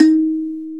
SANZA 1 D#3.WAV